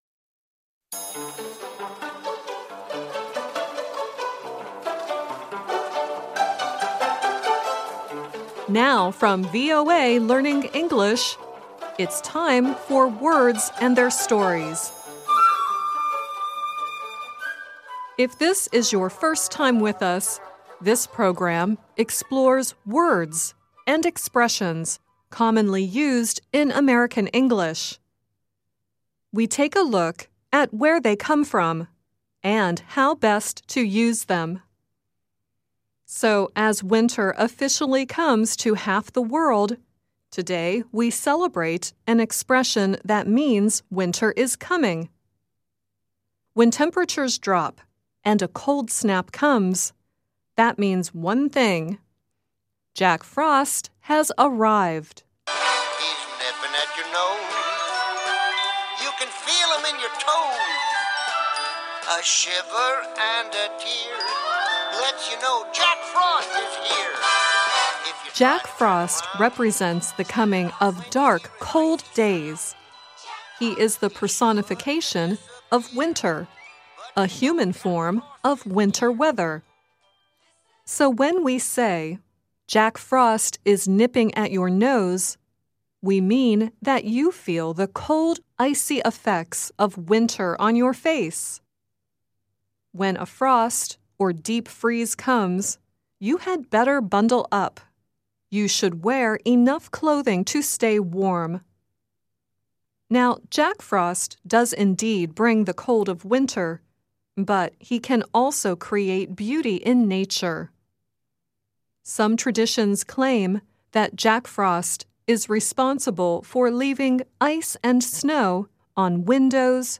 The song earlier in the program is from the 1979 animated television "Jack Frost." The song at the end is Nat King Cole singing "The Christmas Song."